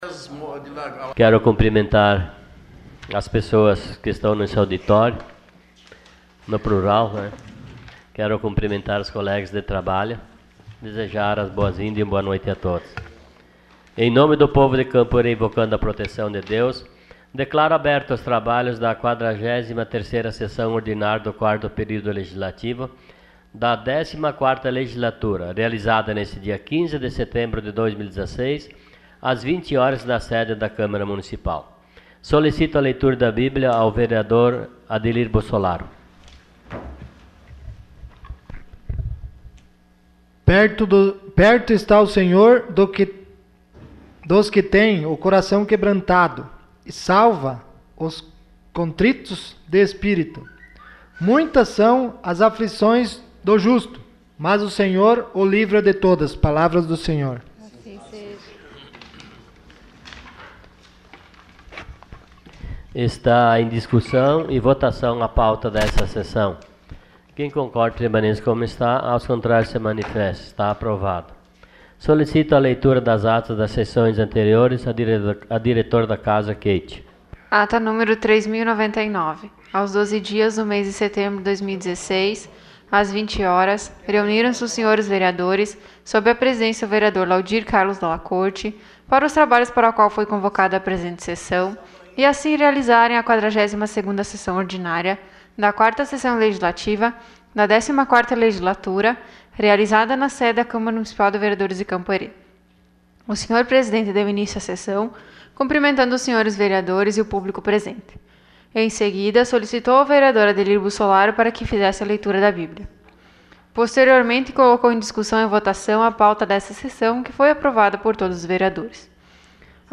Sessão Ordinária dia 15 de setembro de 2016.